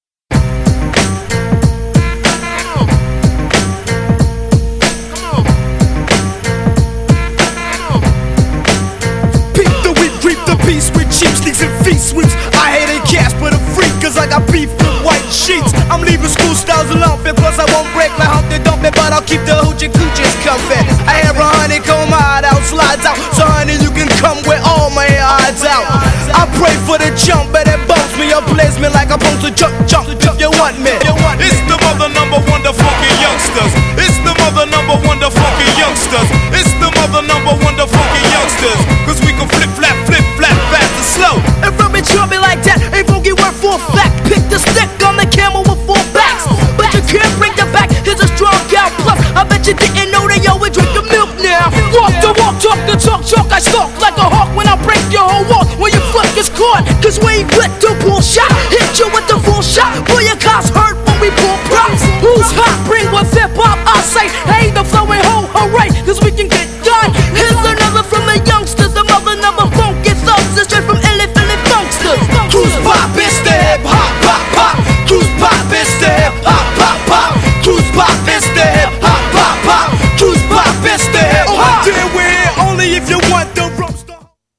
GENRE Hip Hop
BPM 86〜90BPM